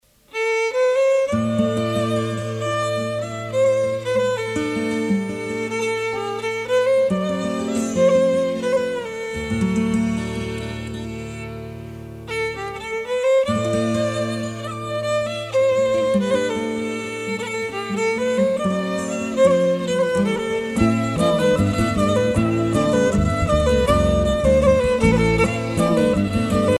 danse : plinn
Pièce musicale éditée